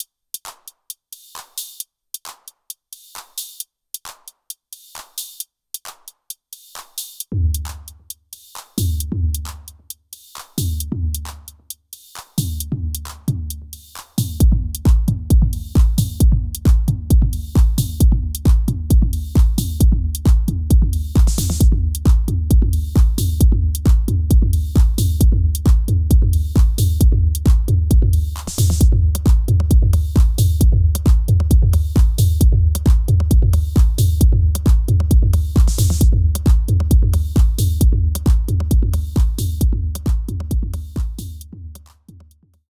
Syntakt doing a 909ish groove (this one uses both of my recent hihat sounds - tom is still WIP)